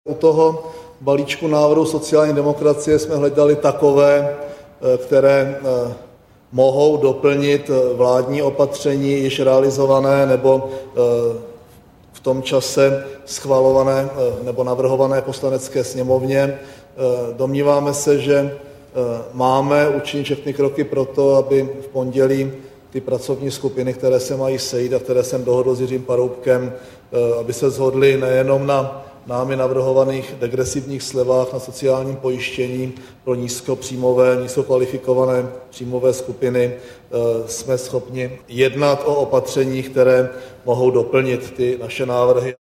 Premiér komentuje nedělní jednání NERV